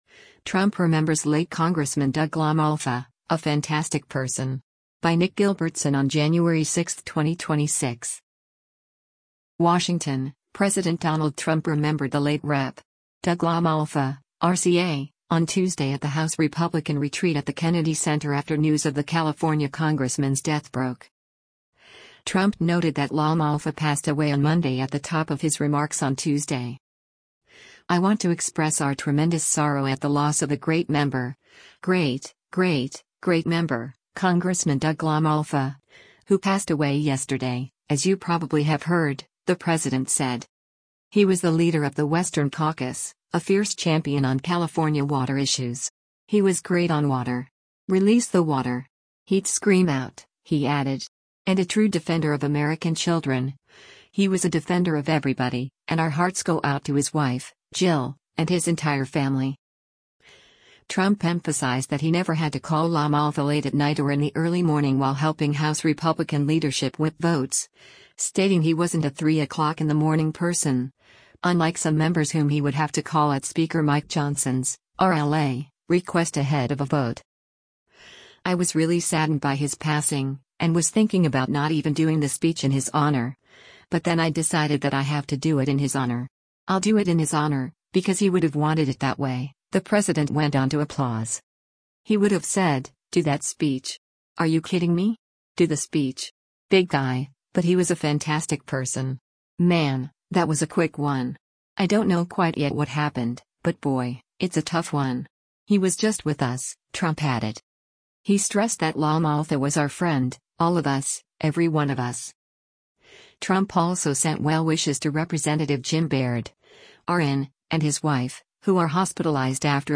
WASHINGTON — President Donald Trump remembered the late Rep. Doug LaMalfa (R-CA) on Tuesday at the House Republican retreat at the Kennedy Center after news of the California congressman’s death broke.
“I was really saddened by his passing, and was thinking about not even doing the speech in his honor, but then I decided that I have to do it in his honor. I’ll do it in his honor, because he would have wanted it that way,” the president went on to applause.